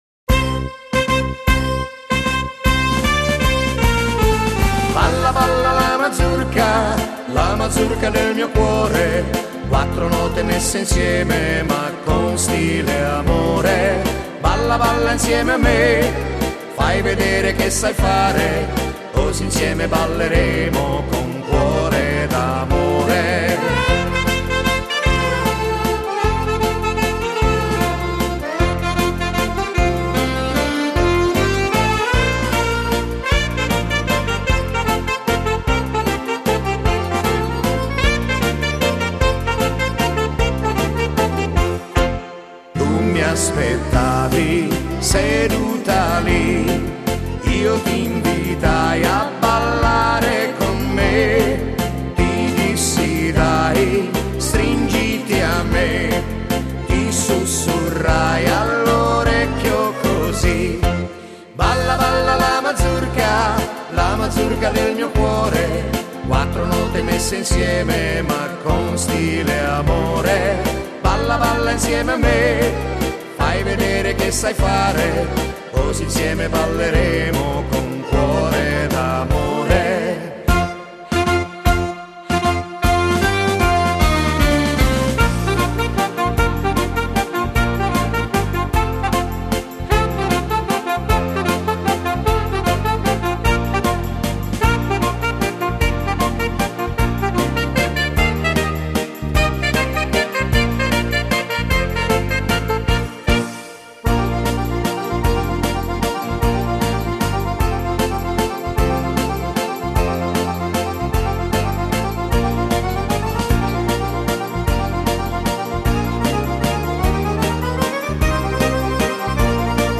Genere: Mazurka